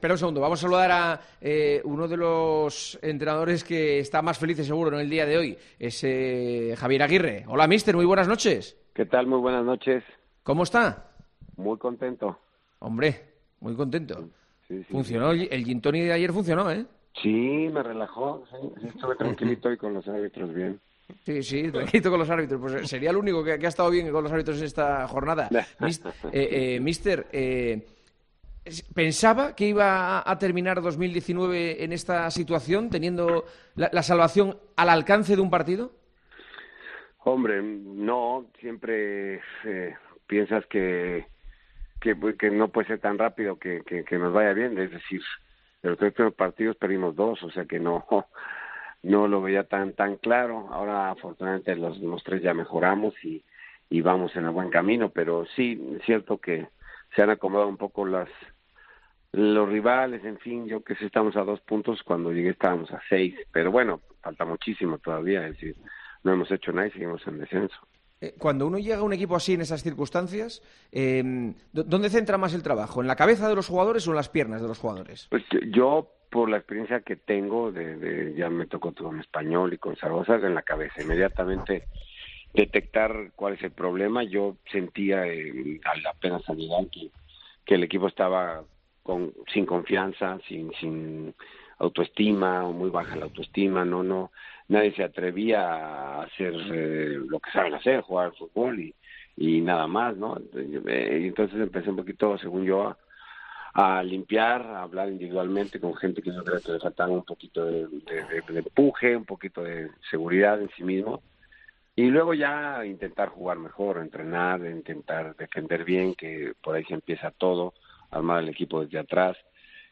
"Piensas que no puede ser tan rápido que nos vaya bien. Falta muchísimo todavía, seguimos en descenso", dijo el técnico mexicano este domingo en el Tertulión de Tiempo de Juego, con Juanma Castaño.